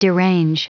Prononciation du mot derange en anglais (fichier audio)
Prononciation du mot : derange